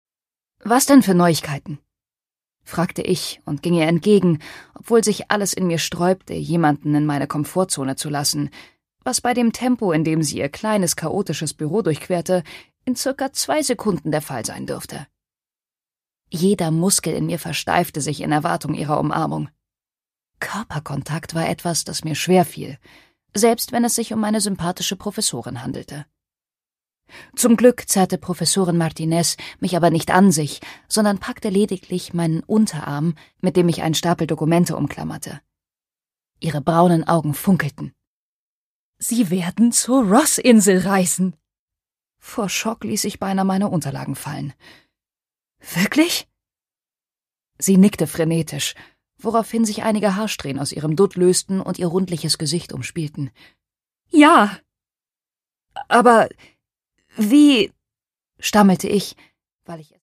Greta Milán: The Penguin Paradox (Ungekürzte Lesung)
Produkttyp: Hörbuch-Download